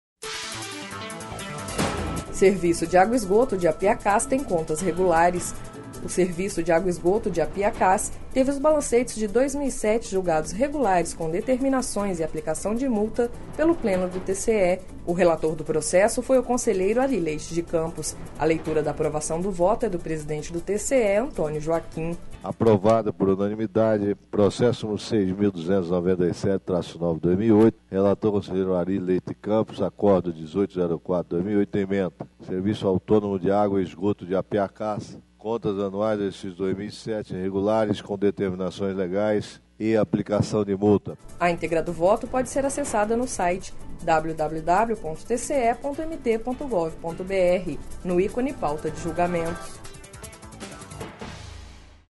Sonora: Antonio Joaquim – presidente do TCE-MT